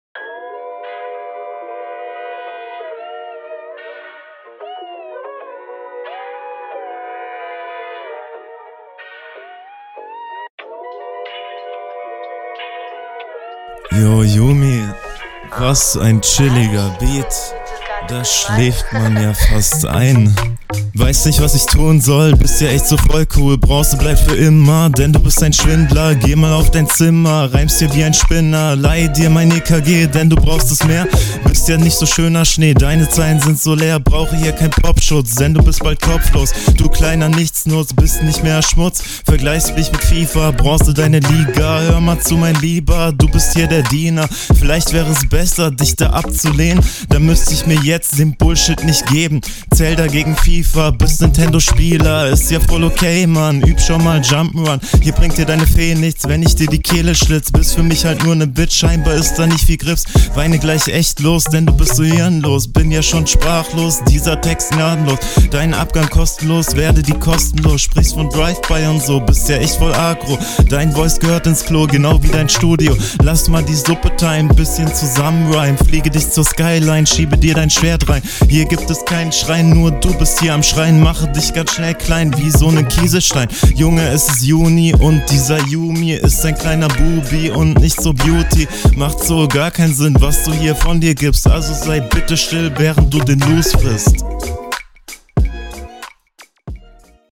Warum der Autotune?